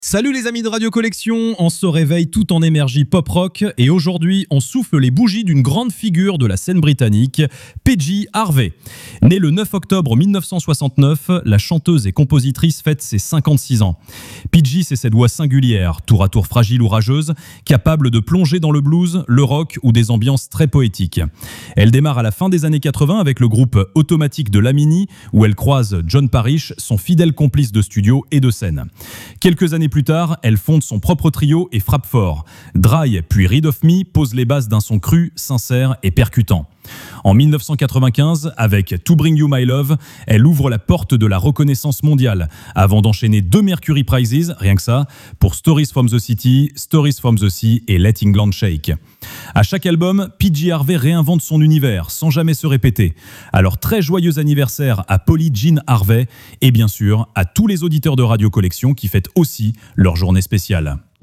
Vous écoutez la chronique Pop Rock de Radio Collection, la webradio gratuite et sans pub qui diffuse les plus grands classiques et les nouveautés en qualité Hi-Fi.